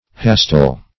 Search Result for " hastile" : The Collaborative International Dictionary of English v.0.48: Hastile \Has"tile\ (h[a^]s"t[imac]l or -t[i^]l), a. [L. hasta a spear.]